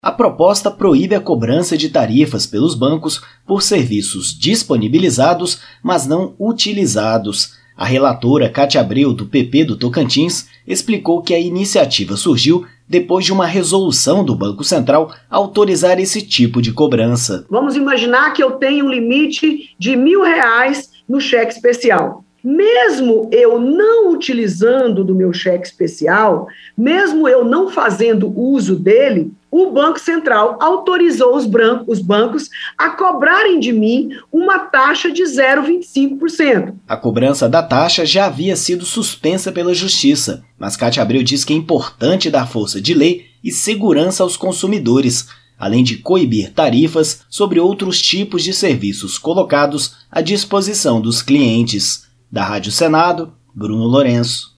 A Comissão de Assuntos Econômicos aprovou projeto que impede a cobrança de taxa por serviço bancário não utilizado (PLP 2/2020). A relatora, Kátia Abreu (PP-TO), lembrou que esse tipo de tarifa já foi suspenso pela Justiça, mas é importante dar força de lei e segurança aos contribuintes, além de coibir tarifas sobre outros tipos de serviços colocados à disposição para os clientes.